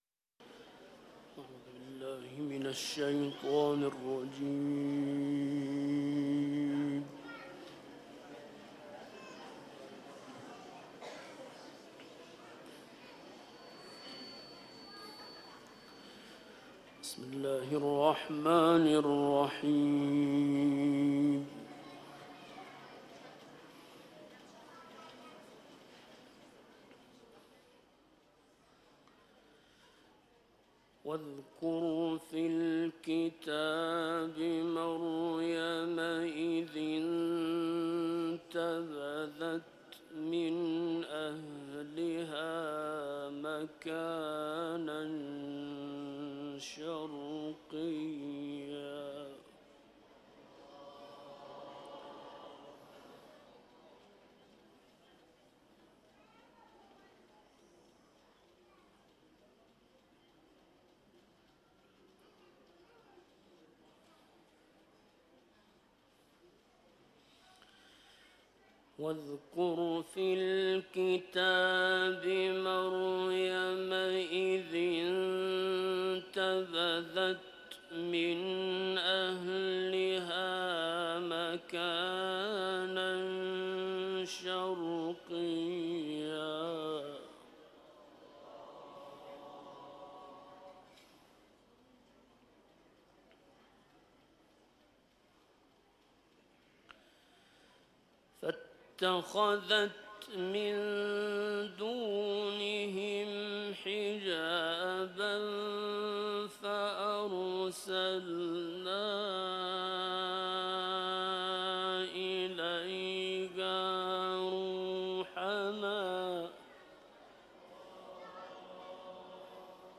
فایل صوتی تلاوت آیات ۱۶ تا ۳۶ سوره مریم